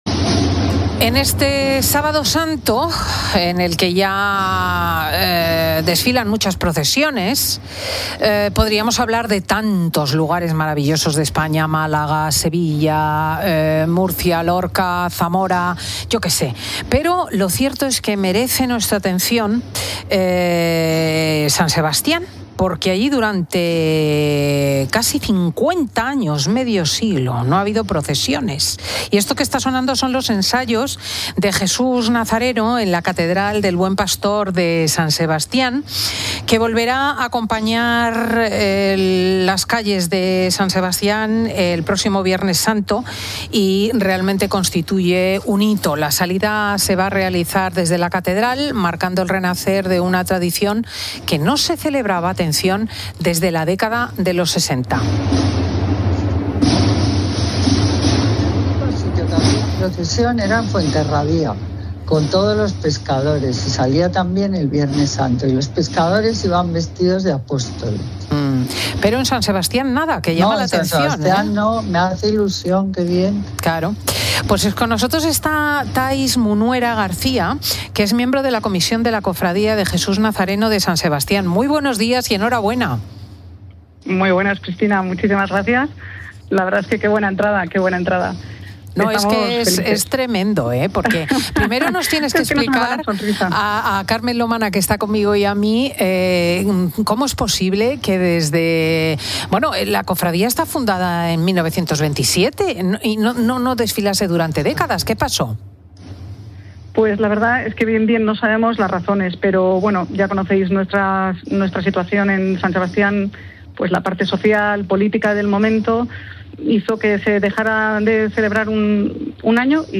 El resurgimiento de esta tradición ha sido el tema central de una entrevista en el programa 'Fin de Semana' de COPE
El regreso de la procesión a San Sebastián no solo revive una antigua tradición, sino que, como apuntaba Cristina López Schlichting al oír una saeta en euskera, es un reflejo de la pluralidad y la riqueza cultural: "Esto es España, señoras y señores".